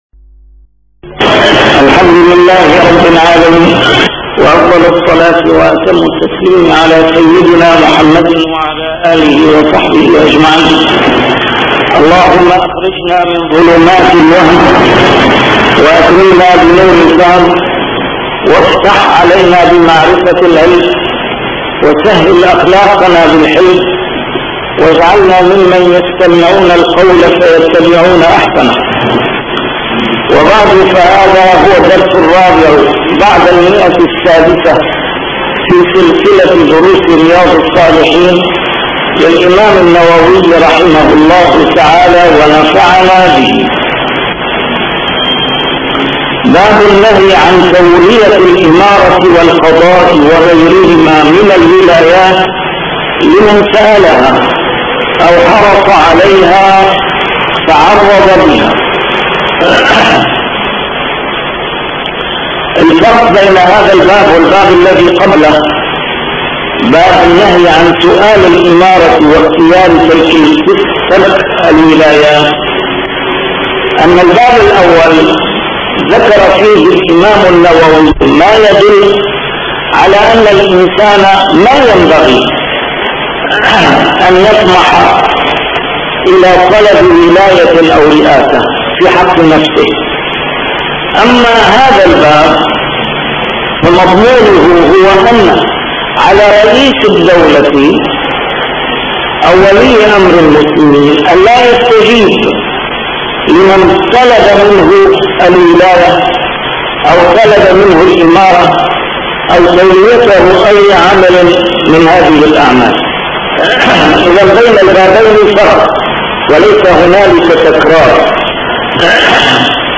A MARTYR SCHOLAR: IMAM MUHAMMAD SAEED RAMADAN AL-BOUTI - الدروس العلمية - شرح كتاب رياض الصالحين - 604- شرح رياض الصالحين: النهي عن تولية الإمارة